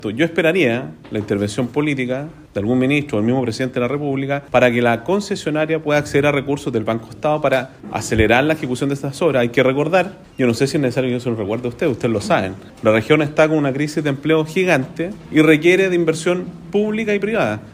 gobernador-sergio-giacaman.mp3